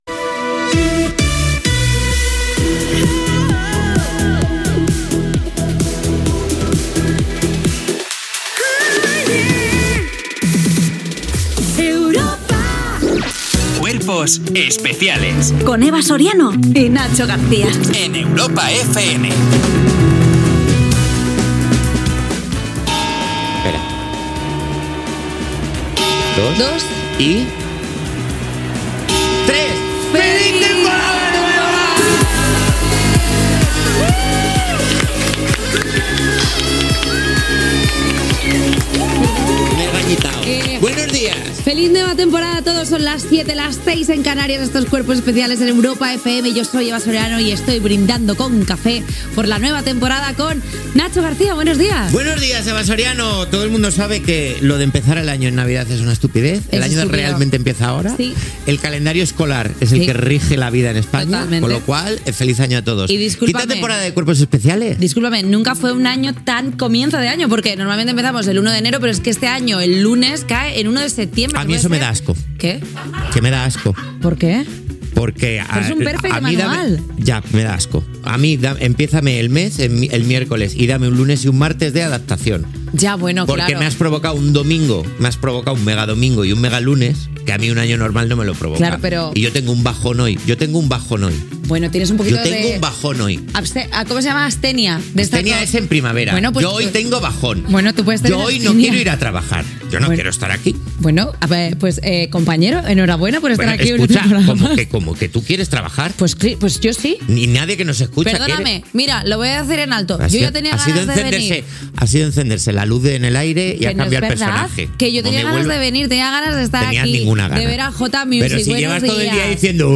Indicatiu de la ràdio, careta del programa, hora benvinguda a la temporada 2025-2026. Diàleg dels presentadors, "El tiempo con Eva Soriano". Resum informatiu.
Entreteniment